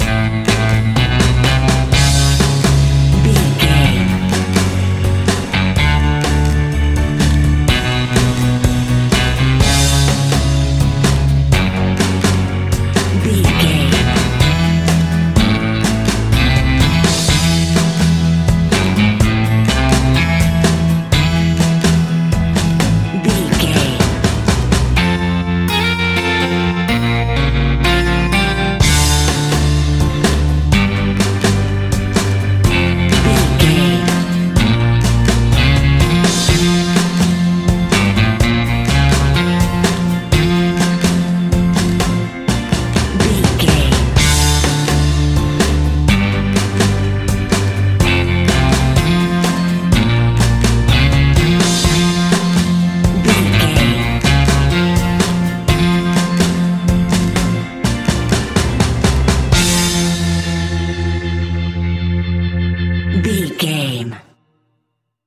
Ionian/Major
fun
energetic
uplifting
acoustic guitars
drums
bass guitar
electric guitar
piano
electric piano
organ